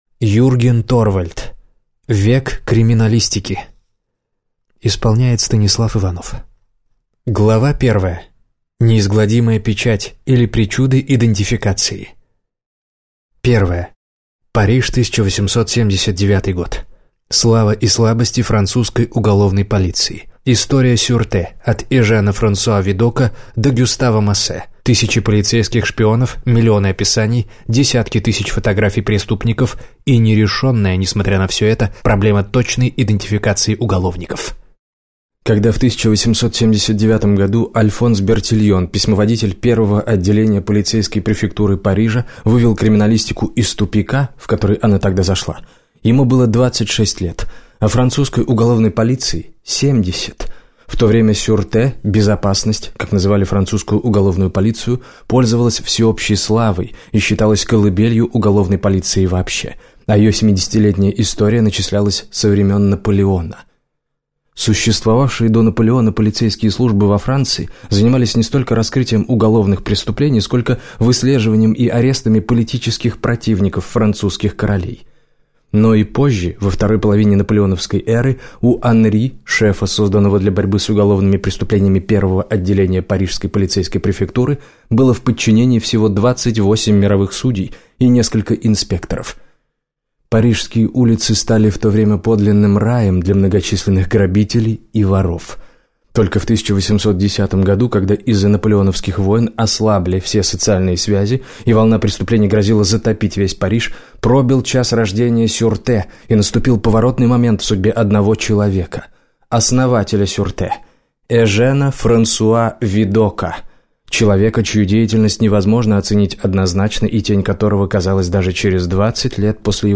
Аудиокнига Век криминалистики - купить, скачать и слушать онлайн | КнигоПоиск